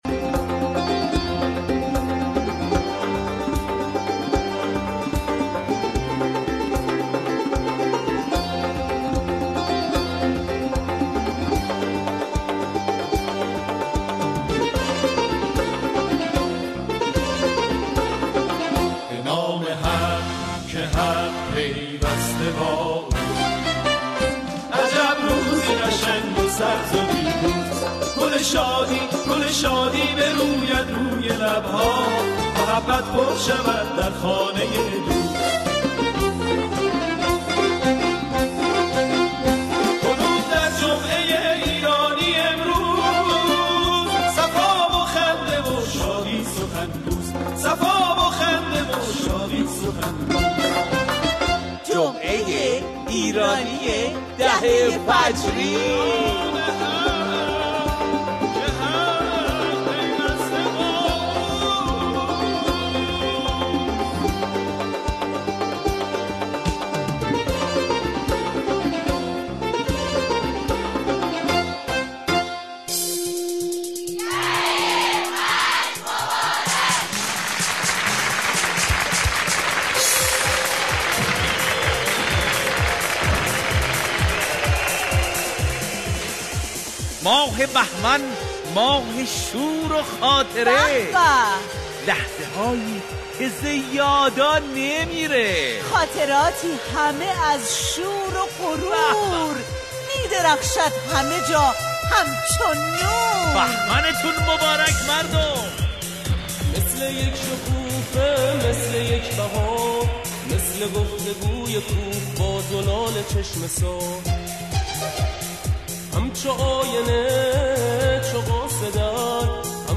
برنامه طنز رادیو ایران